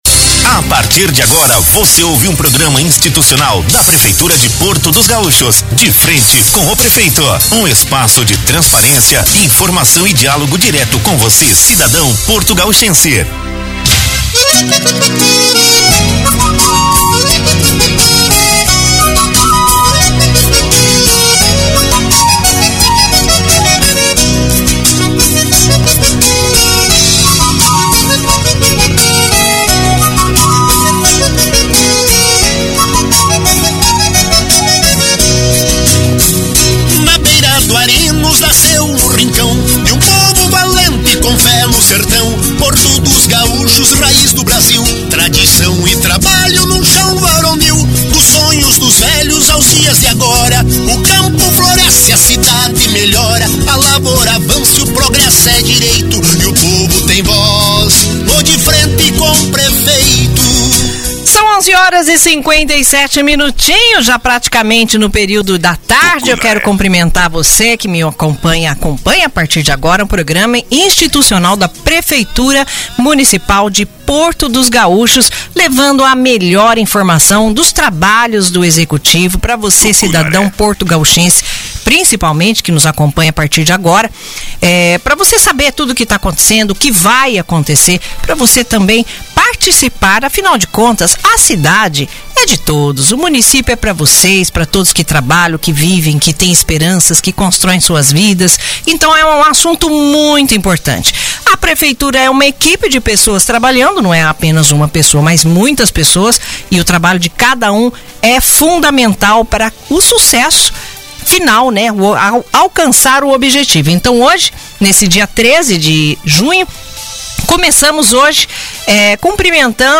Nesta sexta-feira (13), ao meio-dia, foi ao ar pela Rádio Tucunaré mais uma edição do programa institucional “De Frente com o Prefeito”, da Prefeitura de Porto dos Gaúchos. O encontro foi marcado por informações relevantes à população, com a participação especial do secretário de Infraestrutura, Dirceu Fulber, e do secretário de Agricultura, Leandro Oberte Schaedler.